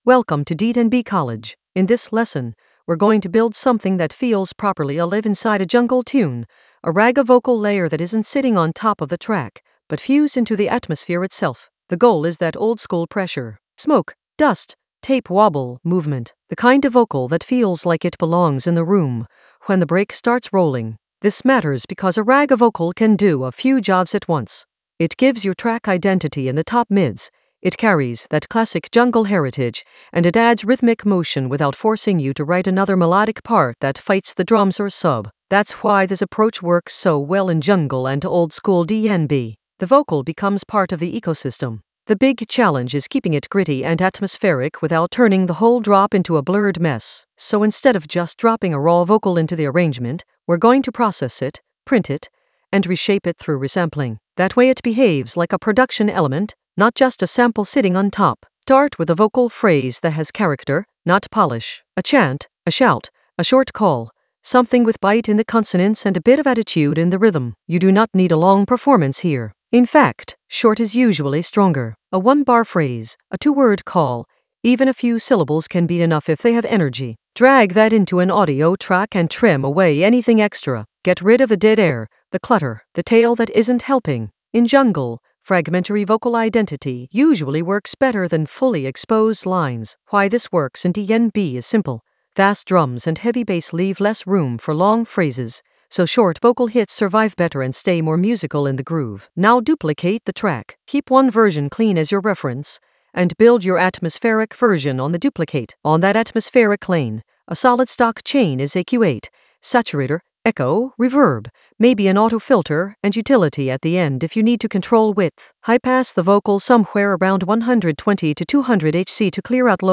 An AI-generated intermediate Ableton lesson focused on Blend a ragga vocal layer with deep jungle atmosphere in Ableton Live 12 for jungle oldskool DnB vibes in the Resampling area of drum and bass production.
Narrated lesson audio
The voice track includes the tutorial plus extra teacher commentary.